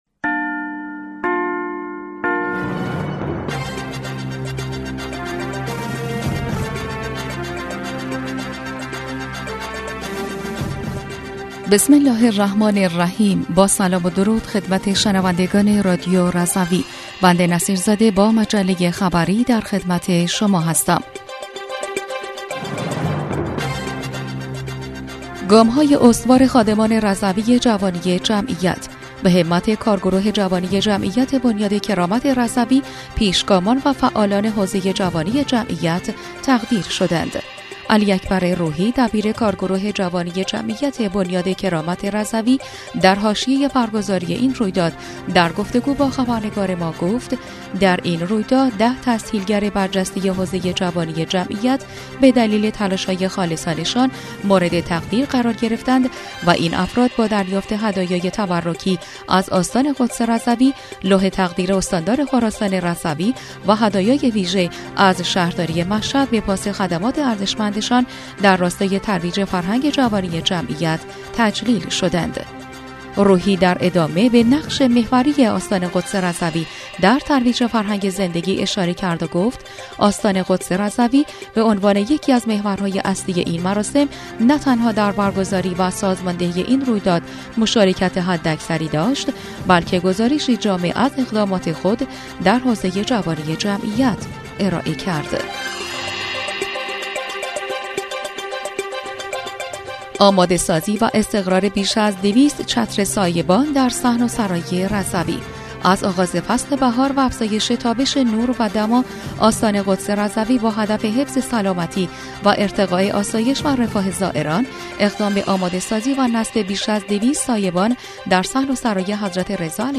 بسته خبری شنبه ۳ خردادماه رادیو رضوی/